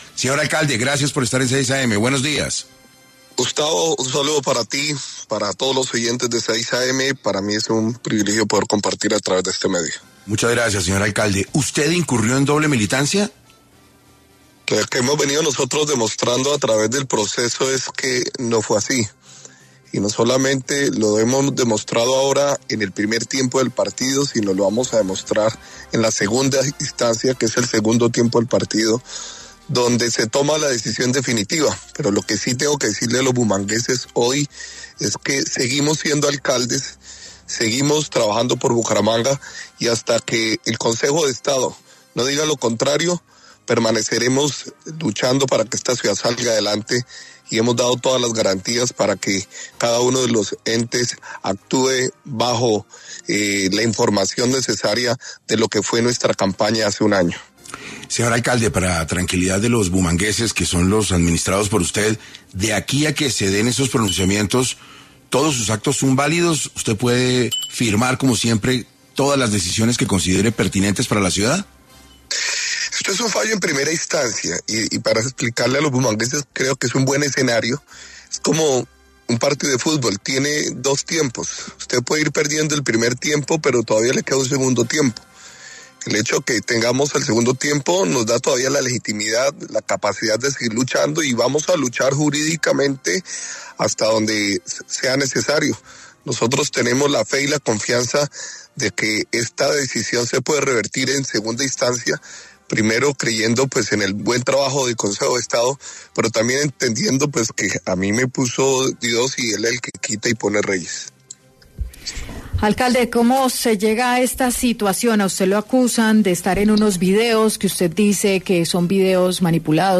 En 6AM de Caracol Radio estuvo Jaime Beltrán, alcalde de la ciudad de Bucaramanga, para hablar sobre cómo recibe la decisión del Tribunal de anular su elección como alcalde de Bucaramanga por haber incurrido en doble militancia.